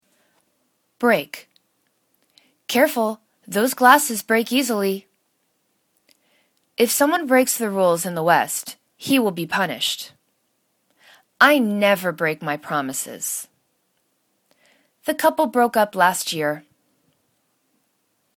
break    /bra:k/    v